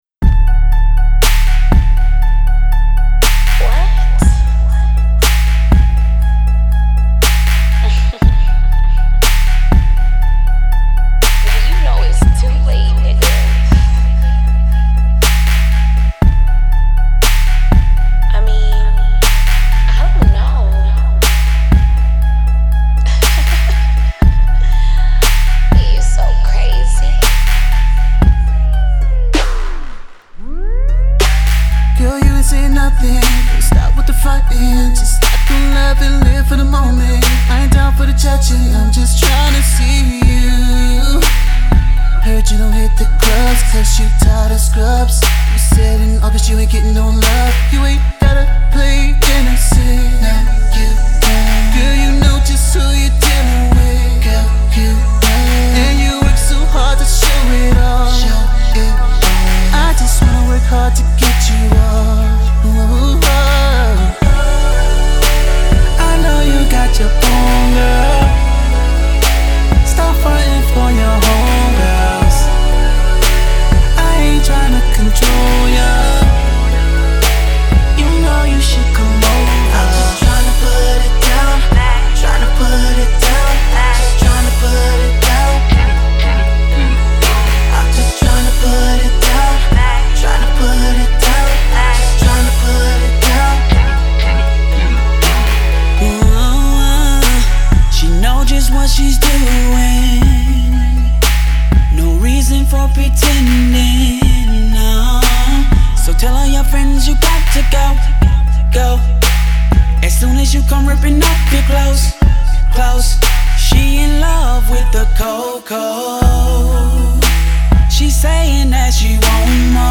Description : The hottest R&B Group out of Atlanta GA